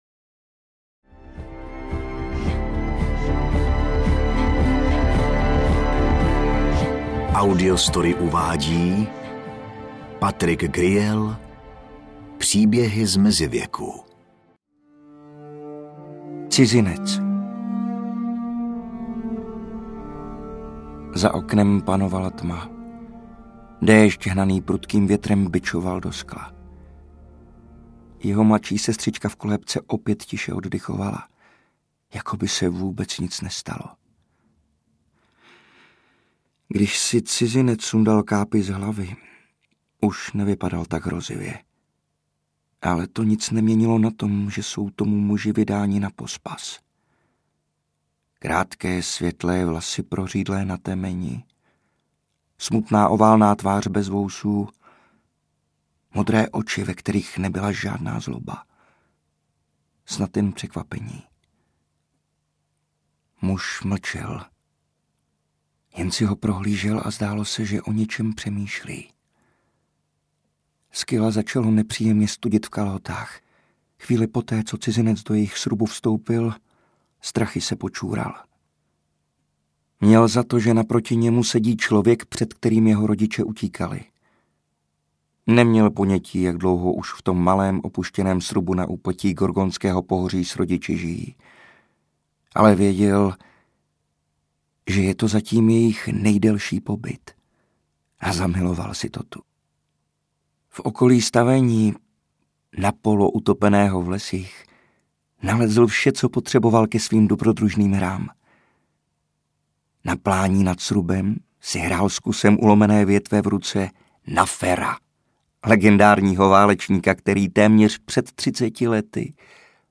Příběhy z Mezivěku audiokniha
Ukázka z knihy